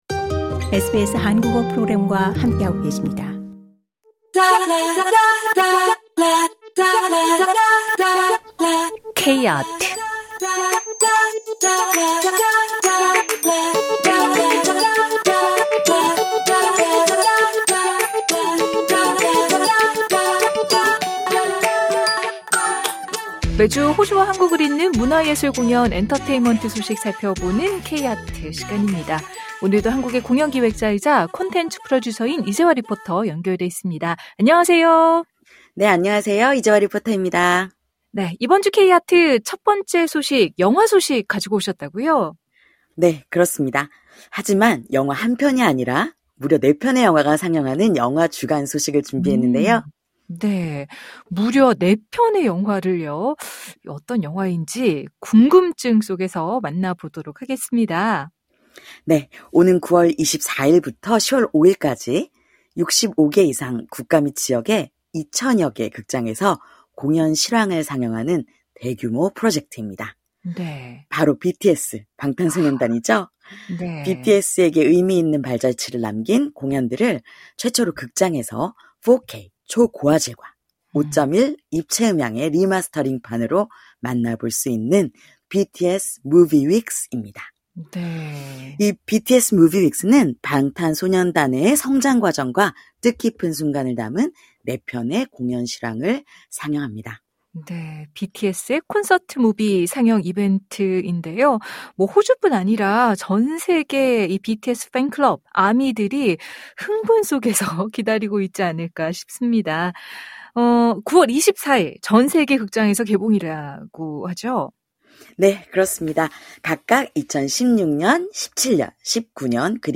한국의 공연 기획자이자 콘텐츠 프로듀서인